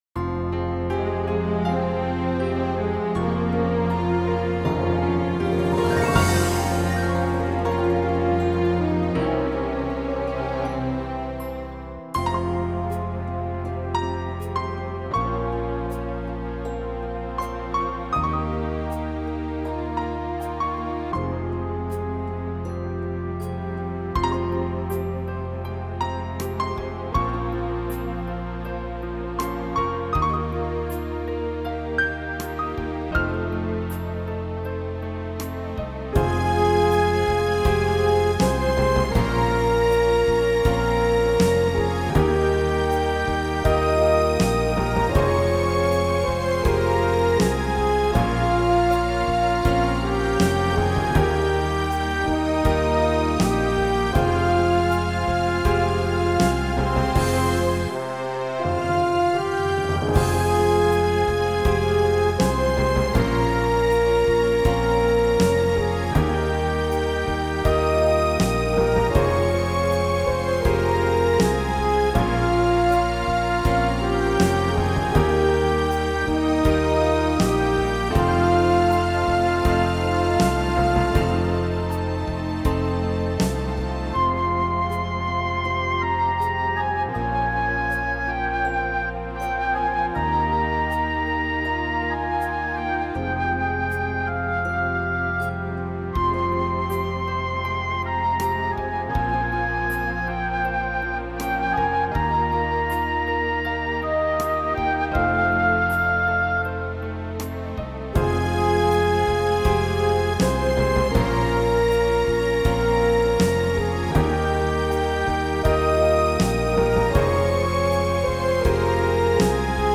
instrumentale song